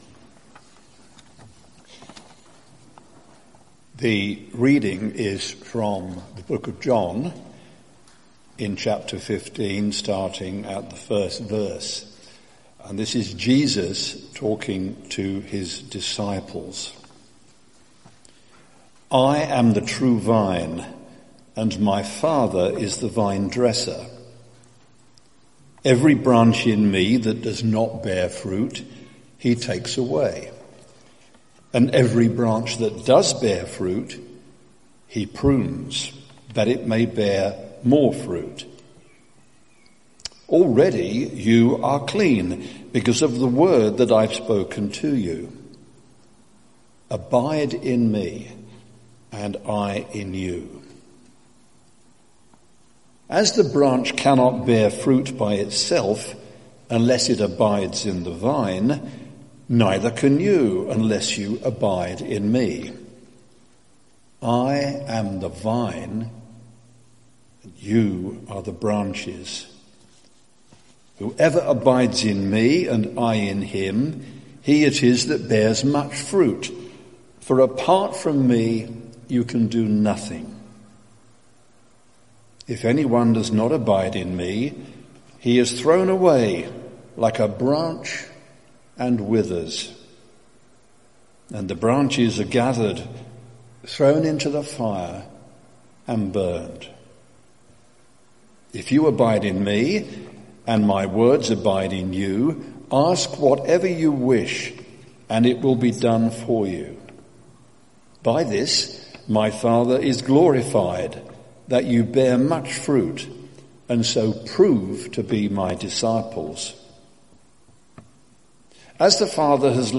Sermon Series: None